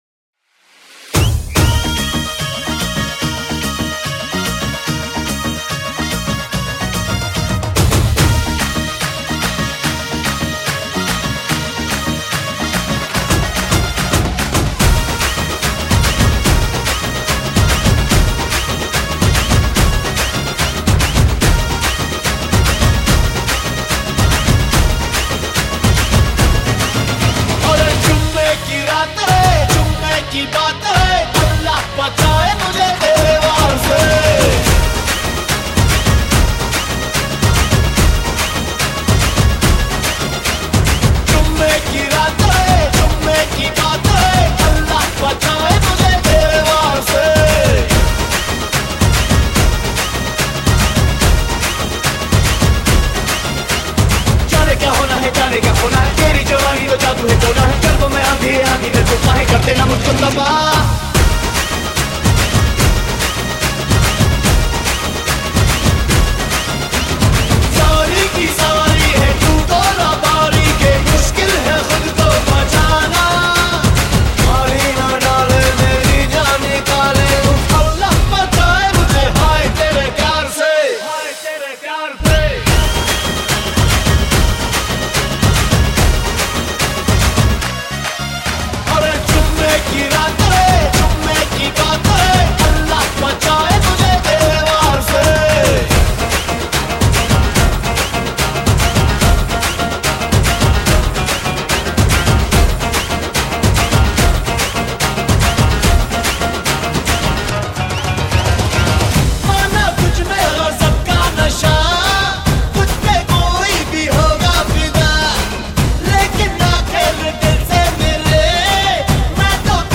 Bollywood Mp3 Music 2014